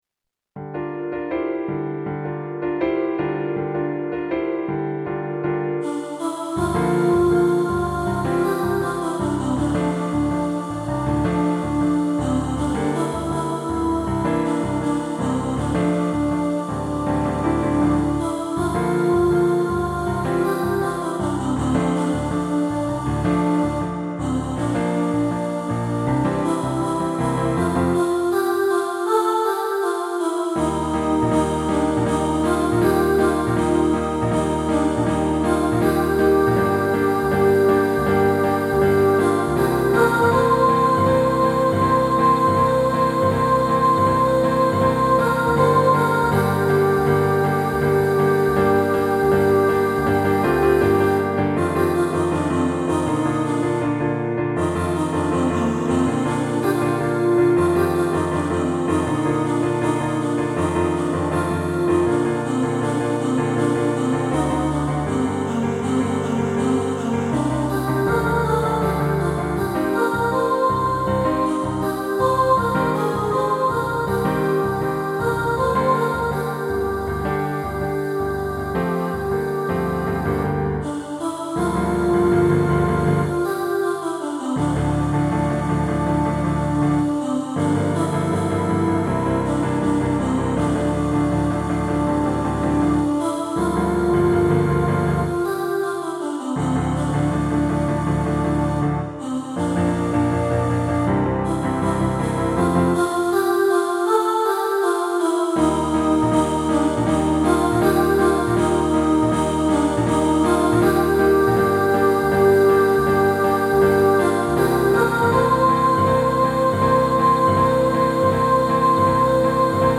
Aquarius-Soprano | Ipswich Hospital Community Choir
Aquarius-Soprano.mp3